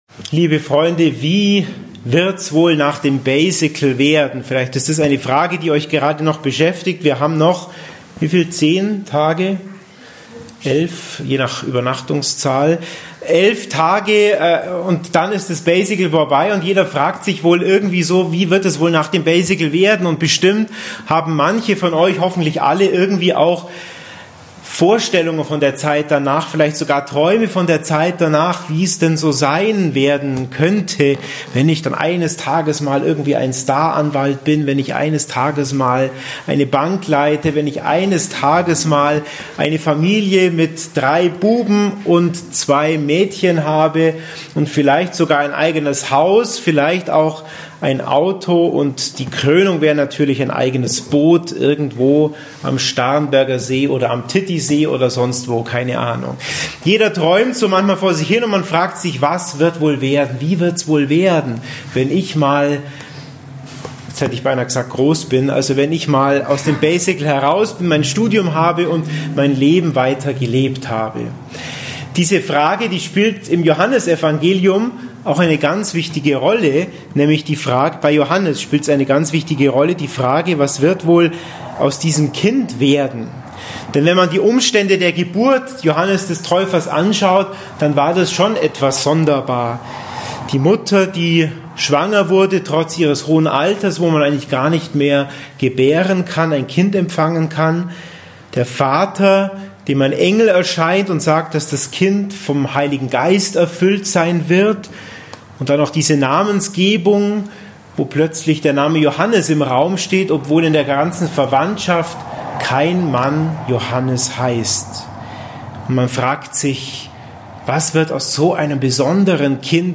Thema · Homəily-Podcast: 25.06.2020 (Hochfest der Geburt Johannes des Täufers)